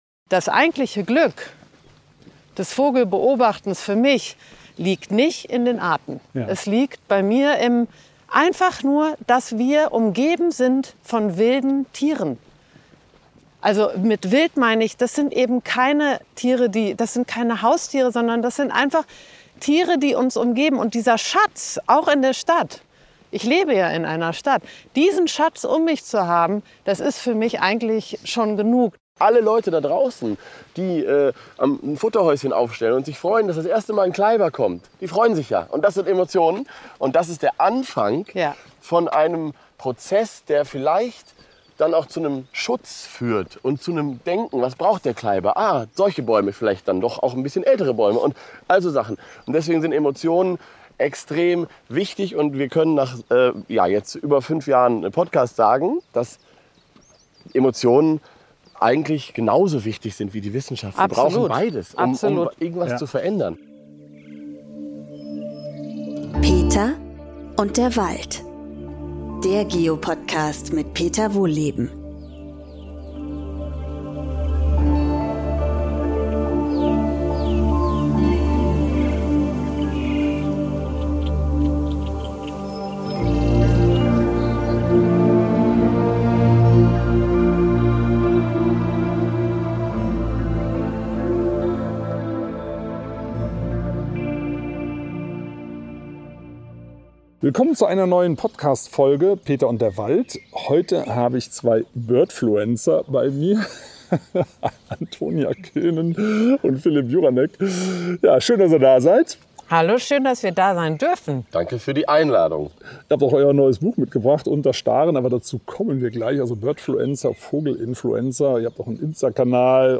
Im Wald entdecken sie zusammen "Frühlingsvögel", erklären den zweideutigen Titel ihres Podcasts „Gut zu Vögeln“ und teilen ihre Ansichten zu kontroversen Themen...